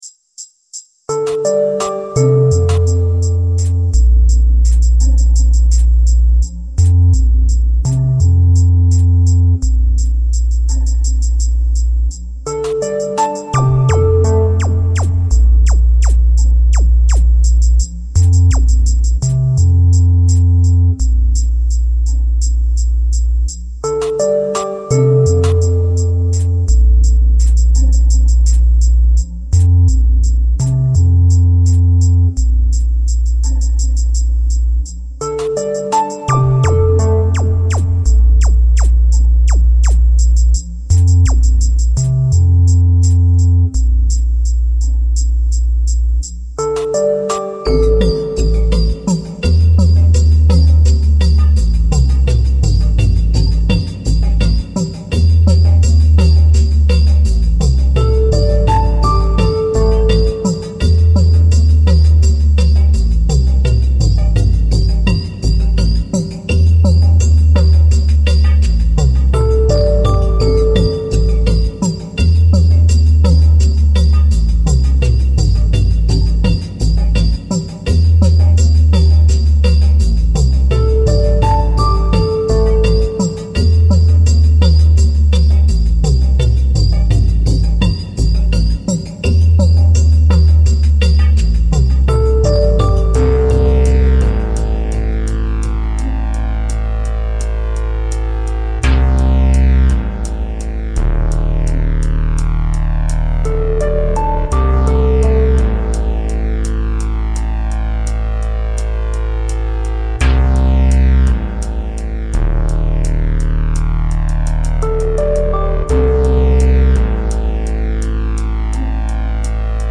File under: Avantgarde